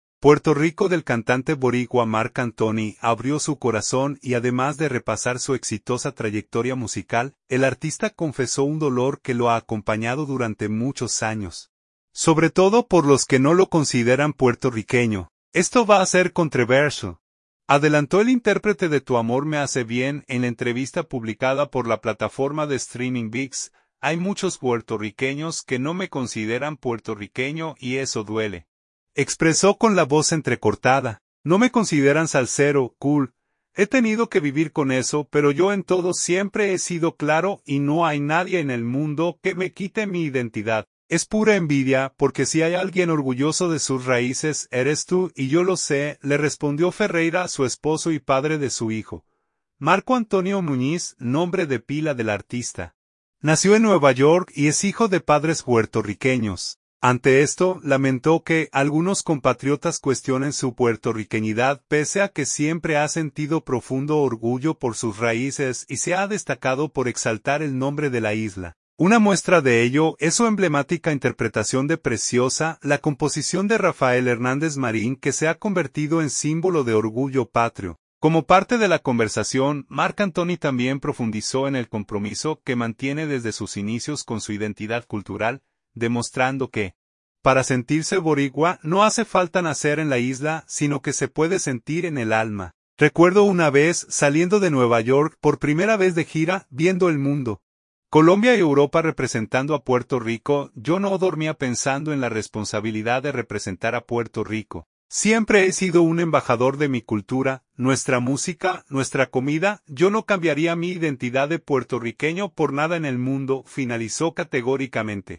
“Esto va a ser controversial”, adelantó el intérprete de “Tu amor me hace bien” en la entrevista publicada por la plataforma de streaming Vix.
“Hay muchos puertorriqueños que no me consideran puertorriqueño y eso duele…”, expresó con la voz entrecortada.